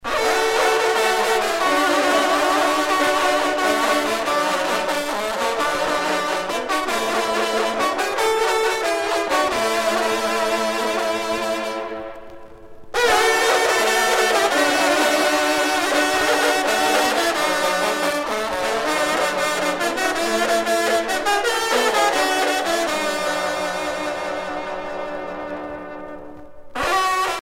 circonstance : vénerie
Pièce musicale éditée